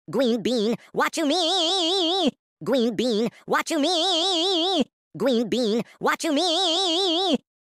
green bean whatchu meeaaannnn Meme Sound Effect